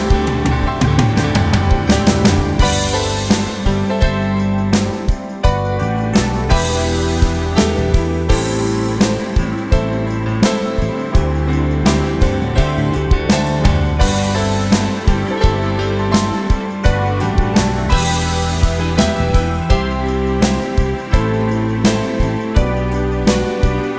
Two Semitones Down Soundtracks 2:57 Buy £1.50